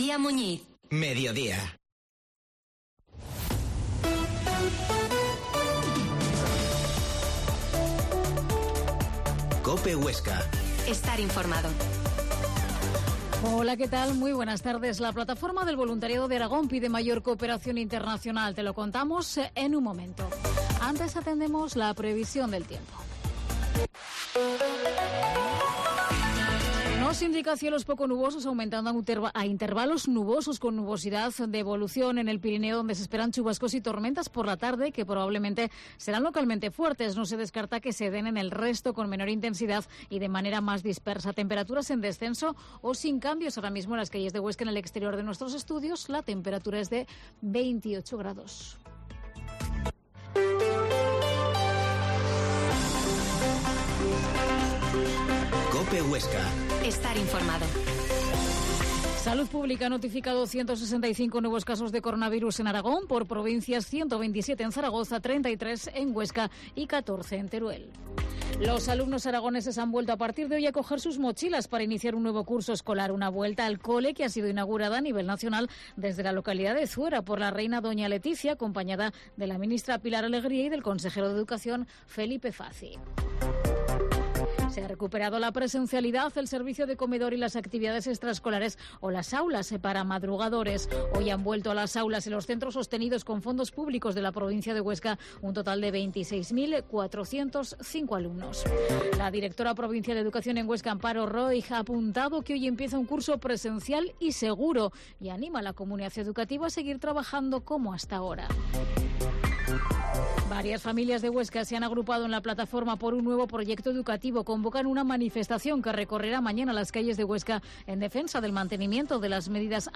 Mediodía en Cope Huesca 13,50h. Entrevista a la Plataforma del Voluntariado de Aragón